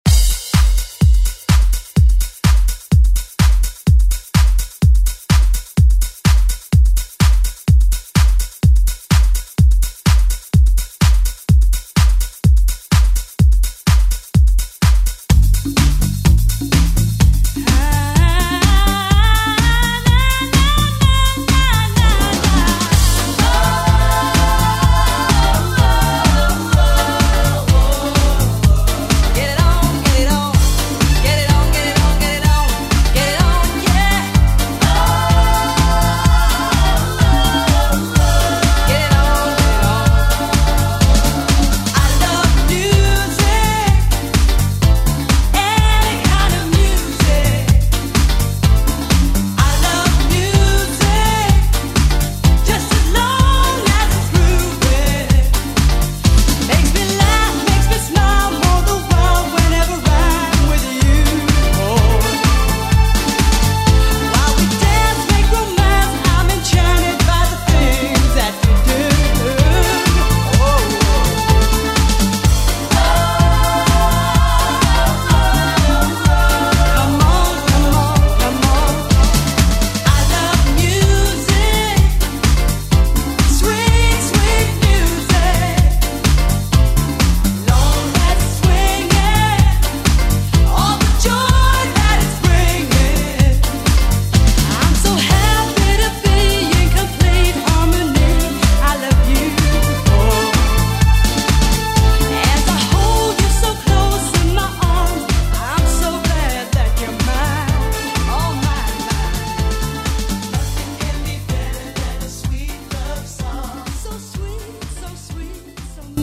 Pop Ballad Funk Soul Music Extended ReDrum Clean 76 bpm
Genre: 70's